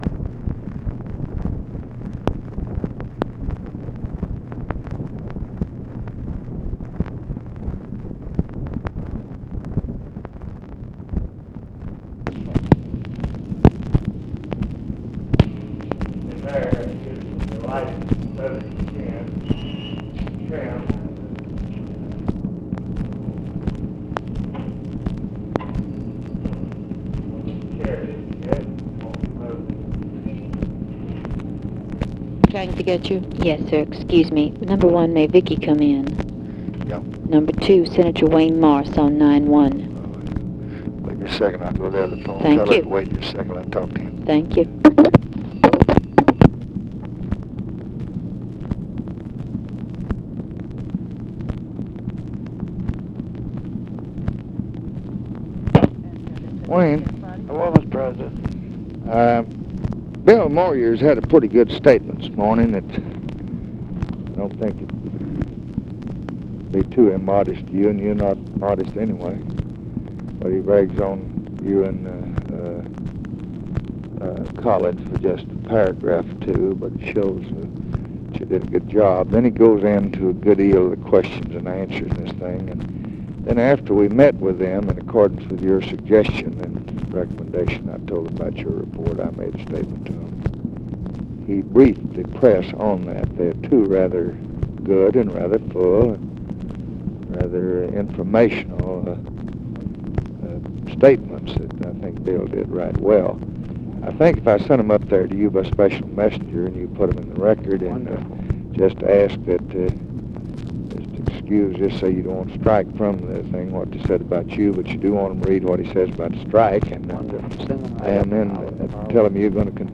Conversation with WAYNE MORSE, OFFICE CONVERSATION and OFFICE SECRETARY, August 30, 1965
Secret White House Tapes